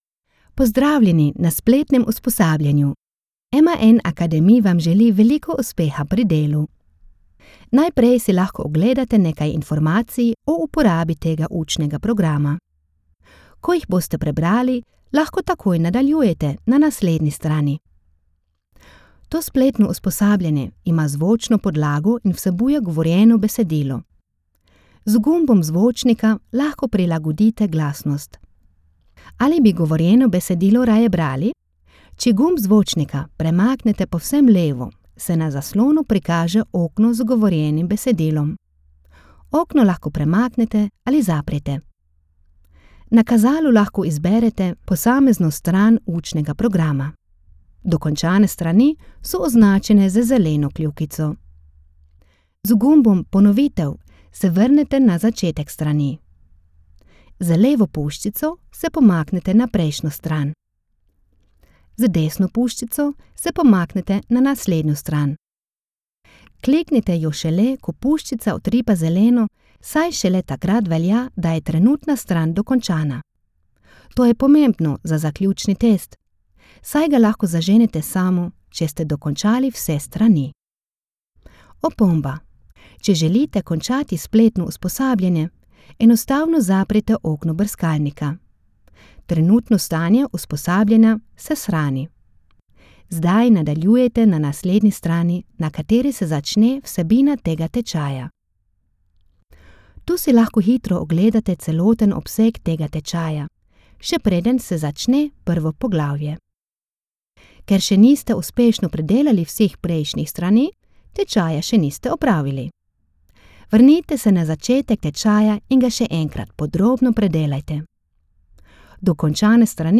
Sprecherin, Übersetzerin, Medienkauffrau
SLO: Intro E-Learning [M.A.N. Academy]